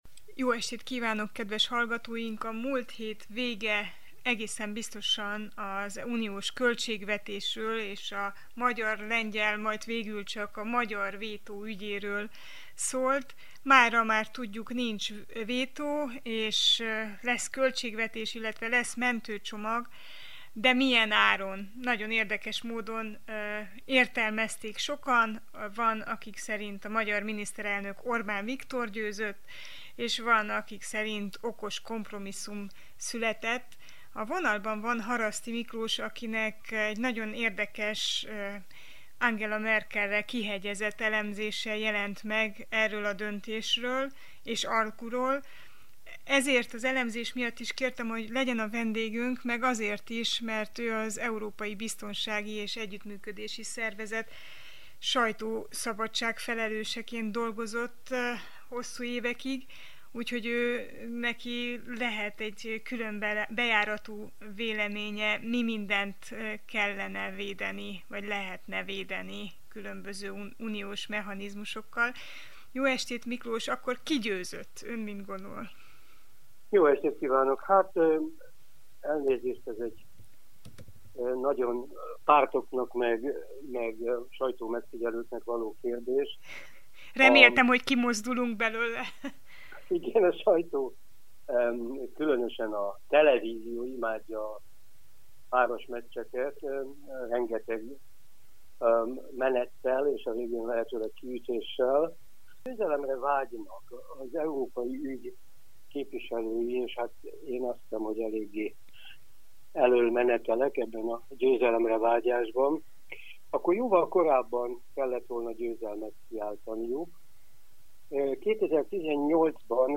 Miért szűkült le minden csak a korrupció egy sajátosan értelmezett, csak az Uniós források közvetlen védelmét célzó formájára? Mi lesz a kisebbségi, emberi jogi védelemmel? Haraszti Miklóssal, az Európai Bizottság és az EBESZ sajtószabadságért felelős korábbi megbízottjával beszélgettünk